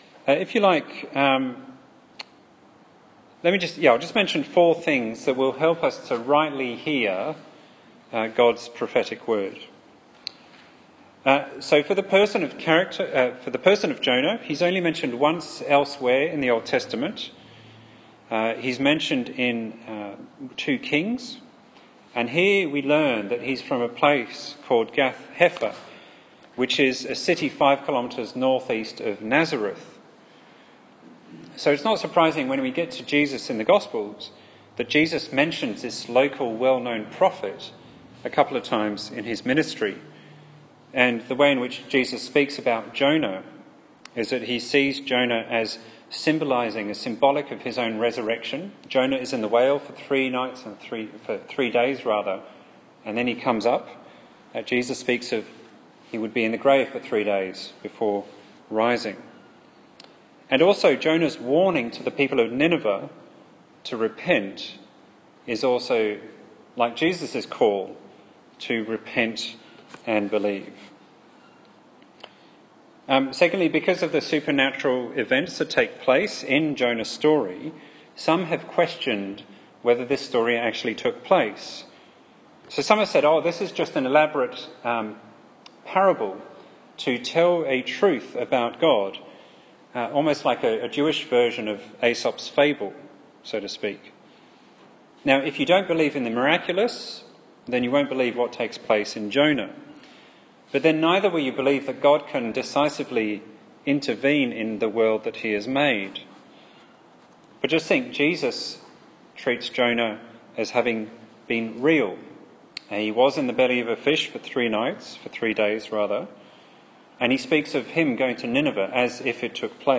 Jonah's Call - Rockingham Anglican Church